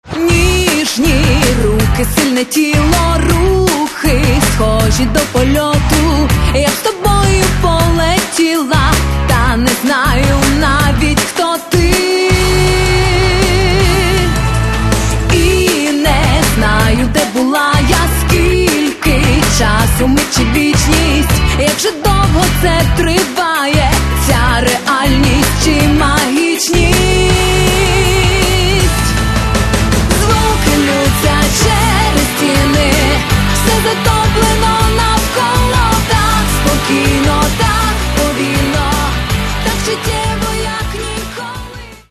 Каталог -> Поп (Легкая) -> Подвижная